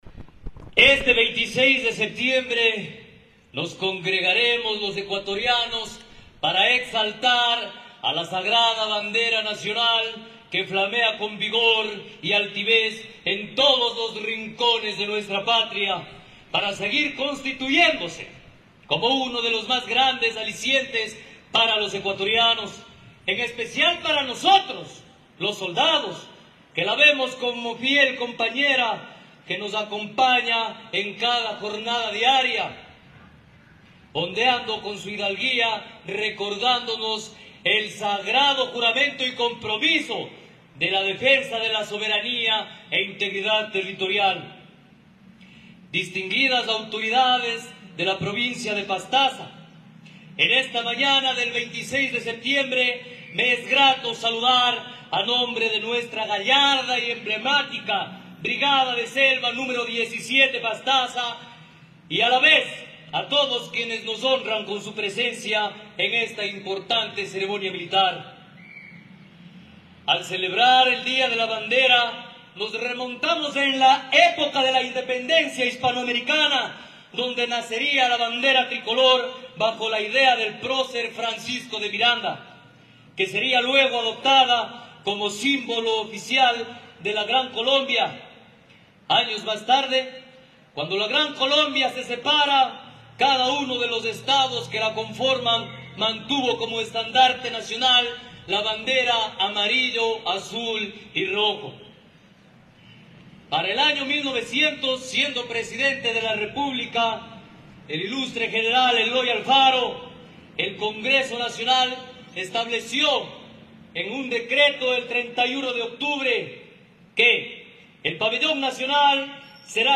La plaza cívica «Héroes del Cenepa» en la ciudad del Puyo-Pastaza, fue el escenario donde la mañana del 26 de septiembre de 2023, militares de la Brigada de Selva Nro. 17 Pastaza realizaron la ceremonia cívico militar por conmemorar el Día de la Bandera Nacional.